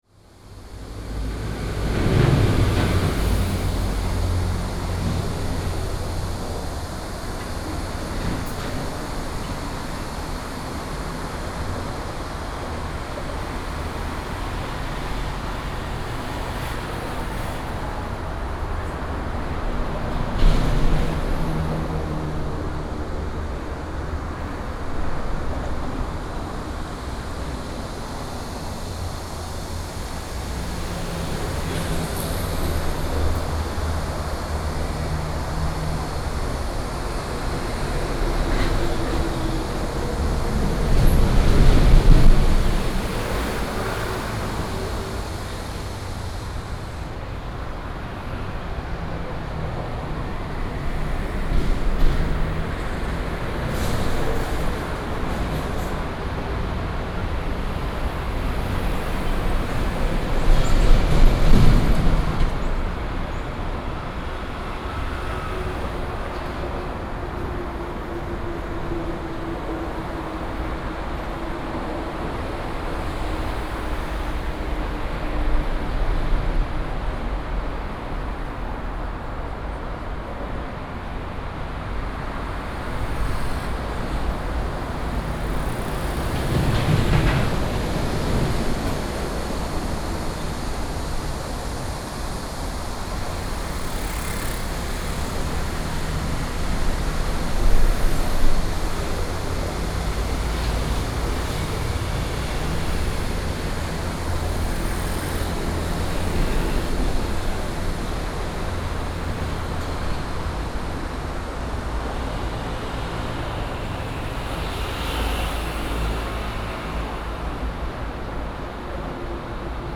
Sony PCM D100+ Soundman OKM II